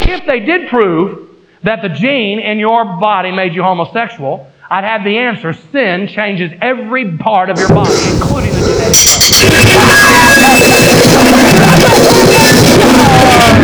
noisecore,